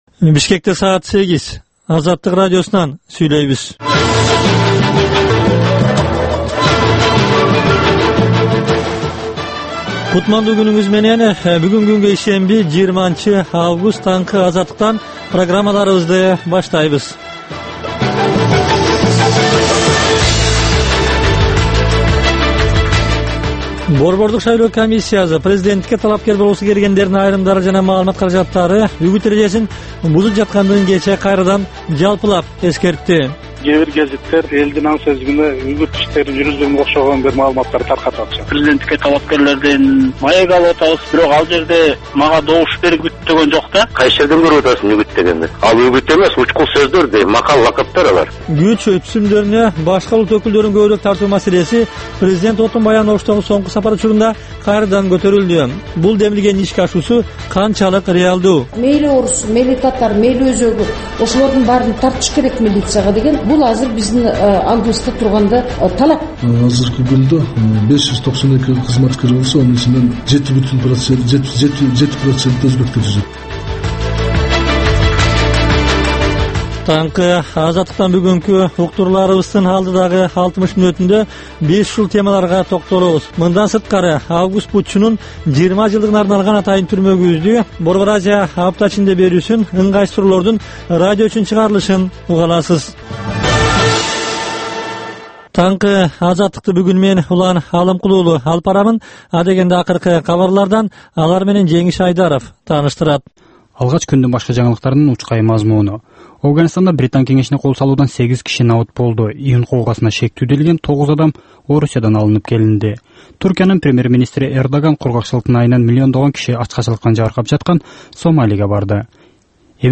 Таңкы 8деги кабарлар